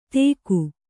♪ tēku